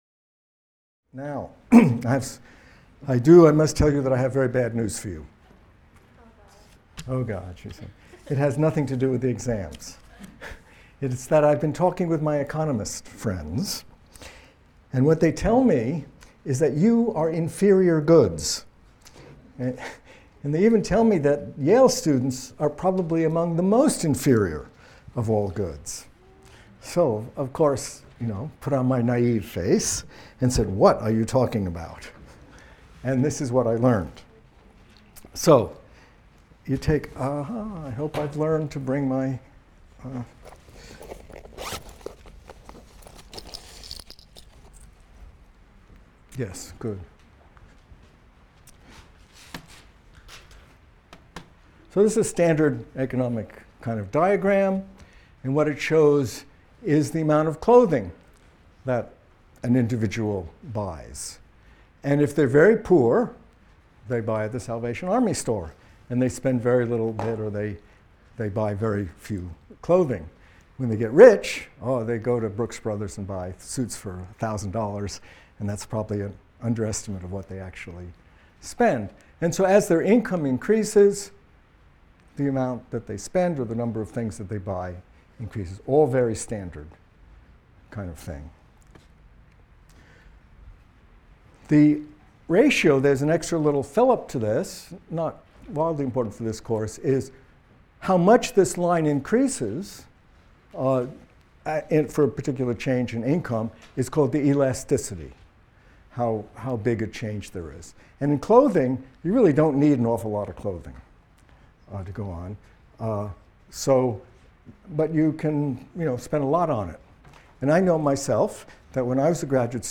MCDB 150 - Lecture 19 - Economic Motivations for Fertility | Open Yale Courses